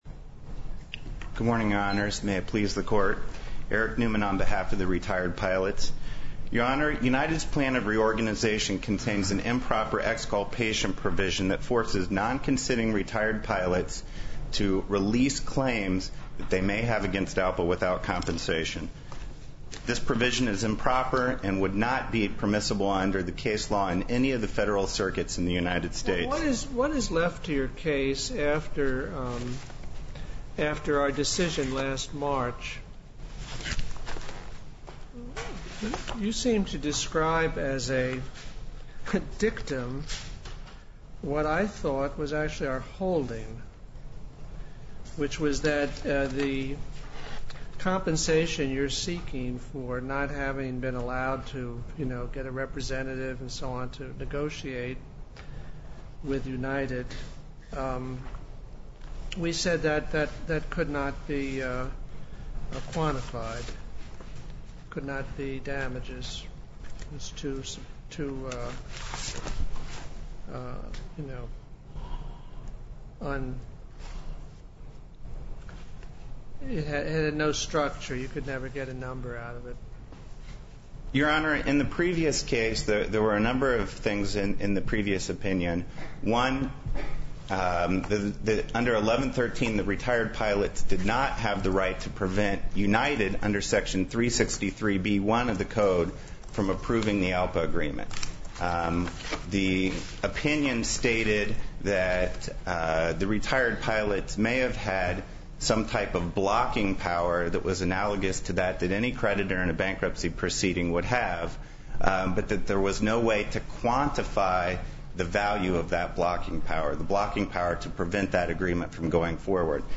In yesterday’s oral argument ( accessible here ), Judge Posner took the lead in peppering the retirees’ counsel with questions.